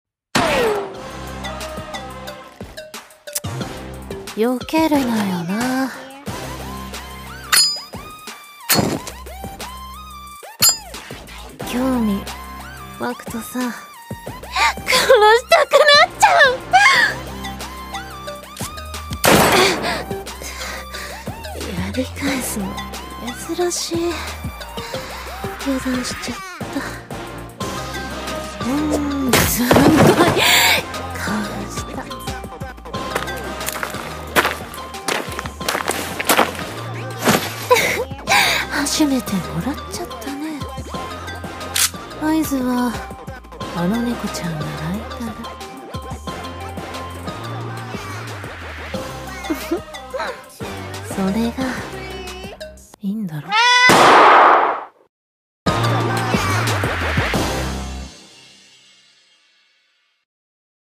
2人声劇